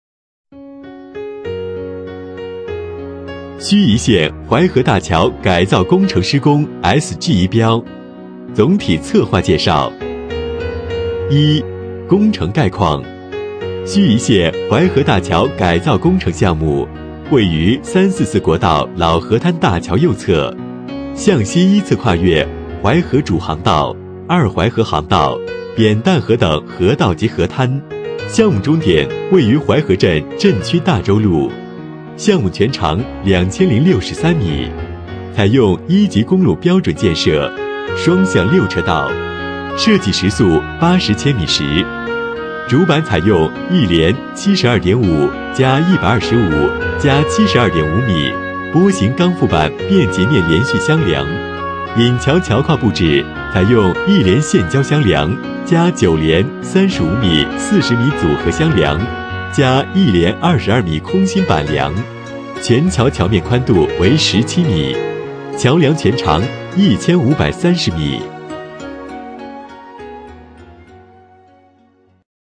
【男31号课件】盱眙县淮河大桥改造工程
【男31号课件】盱眙县淮河大桥改造工程.mp3